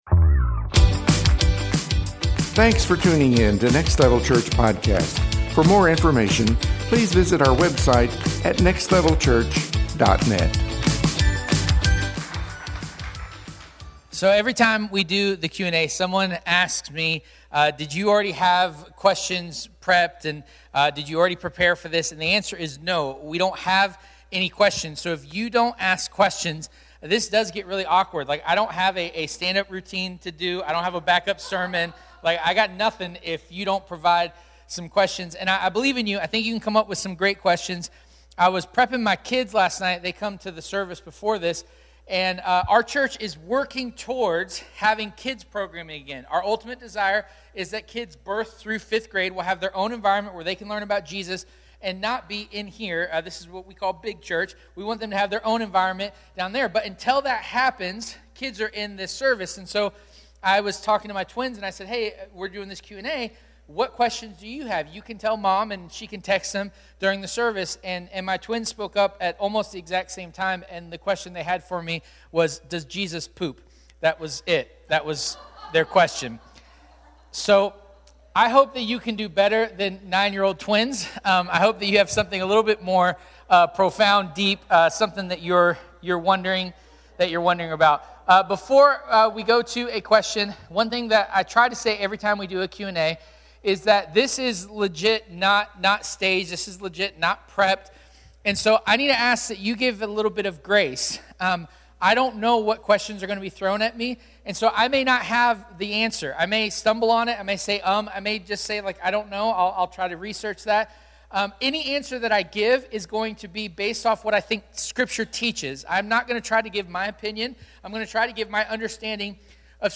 Questions For Christians – Part 4 – 11:30 Service
Series: Questions for Christians Service Type: Sunday Morning